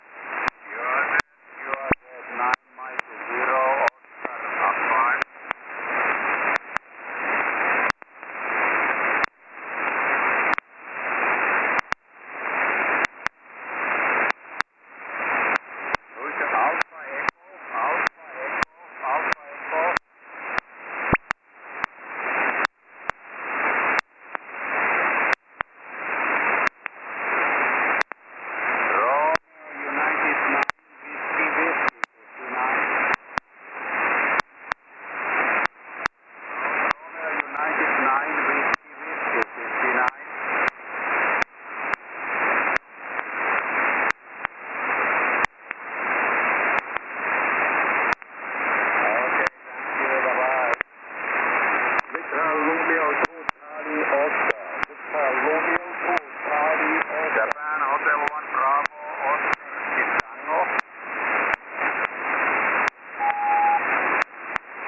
9M0O - Layang Layang (IOTA AS-051) on 18MHz SSB